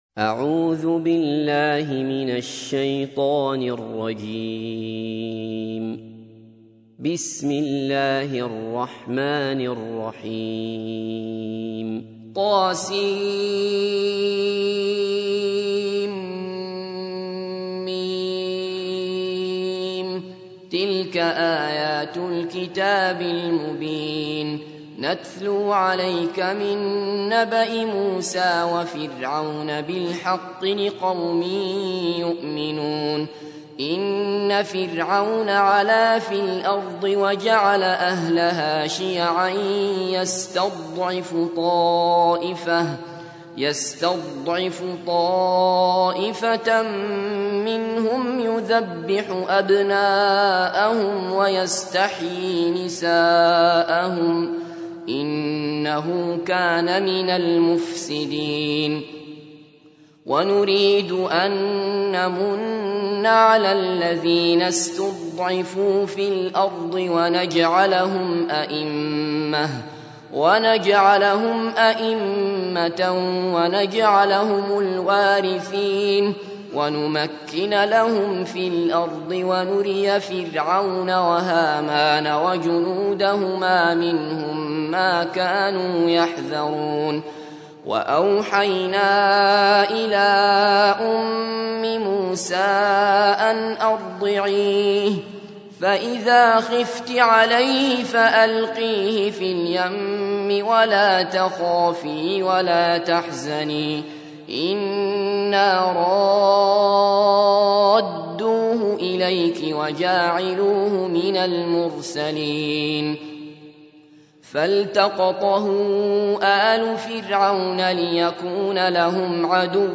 تحميل : 28. سورة القصص / القارئ عبد الله بصفر / القرآن الكريم / موقع يا حسين